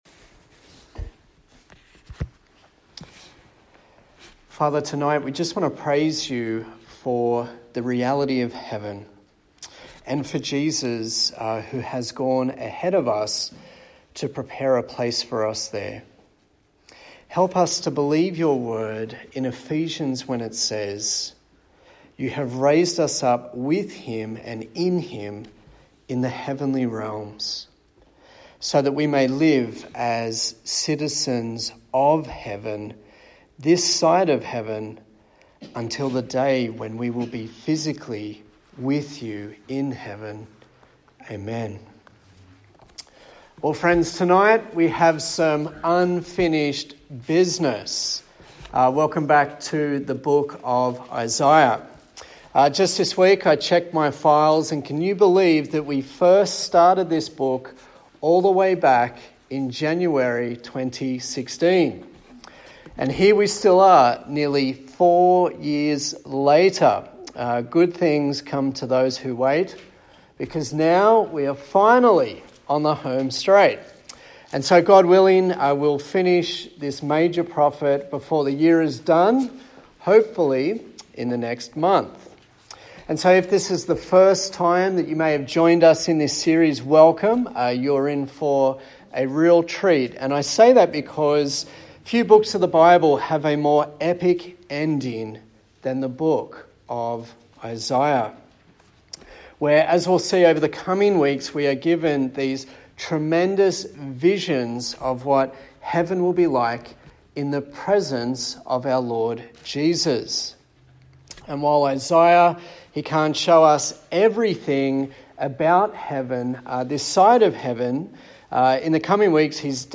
A sermon in the series on the book of Isaiah